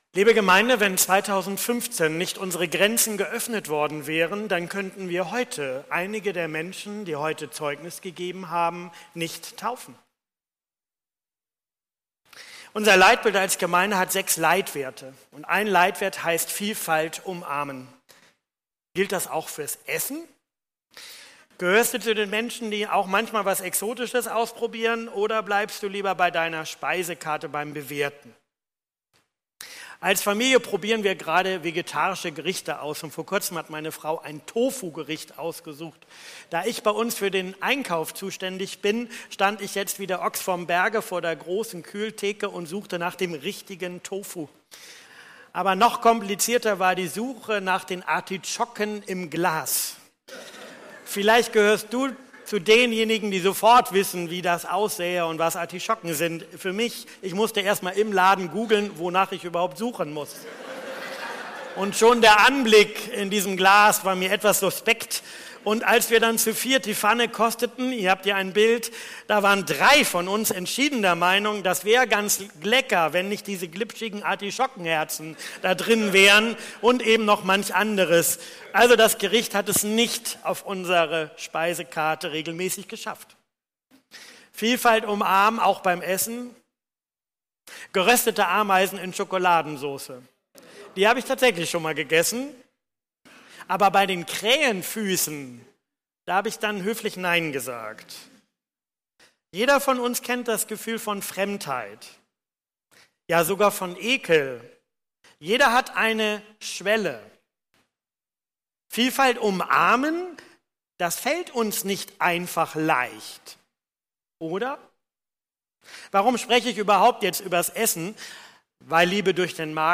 Predigttext: Apostelgeschichte 10